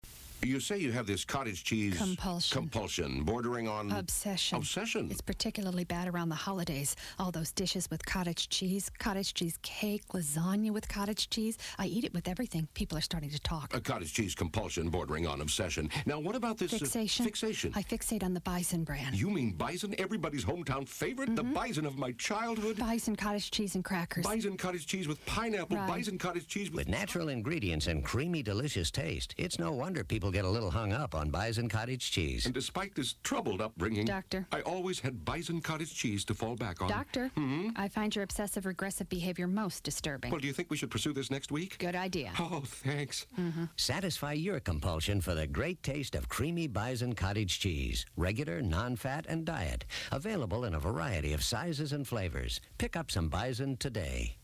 Radio
Cottage Cheese Obsession. Written for a dairy client years ago. Narrator is the person who played the "Dad" on the Patty Duke show. 60 seconds.